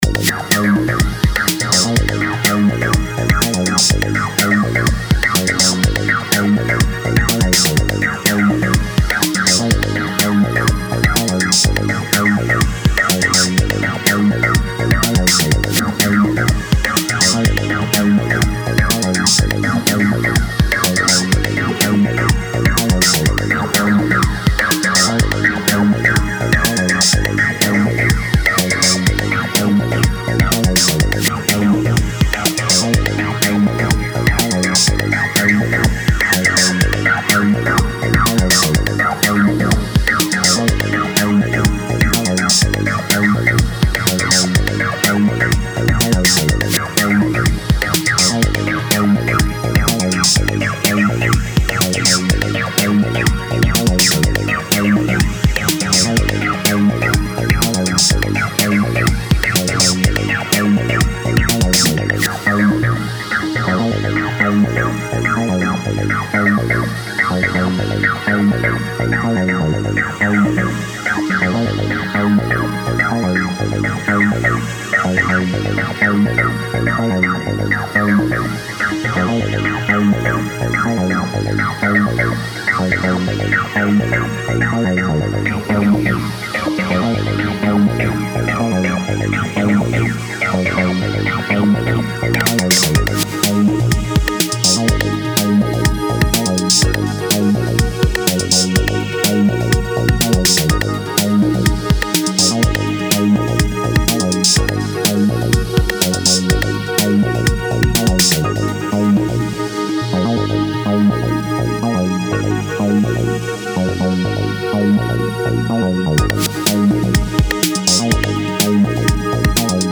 前2作はアシッド・ハウスのフォーカスした印象でしたが、今回はエレクトロに寄せています。